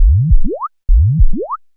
FX 135-BPM 3.wav